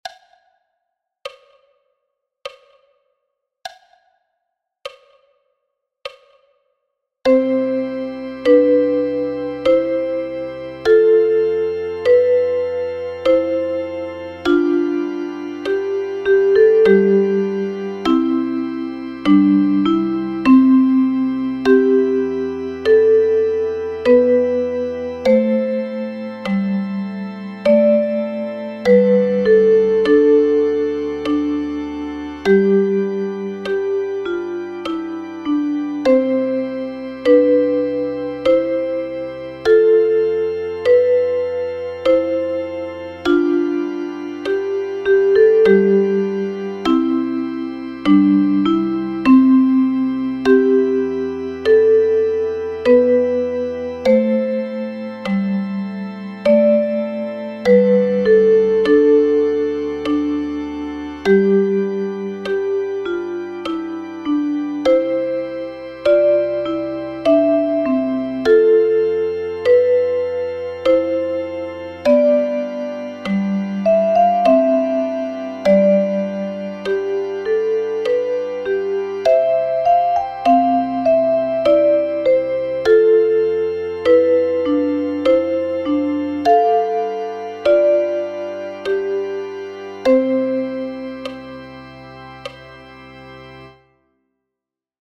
notated as duets for Soprano Recorder and Alto Recorder.